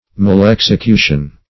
Search Result for " malexecution" : The Collaborative International Dictionary of English v.0.48: Malexecution \Mal*ex`e*cu"tion\, n. [Mal- + execution.]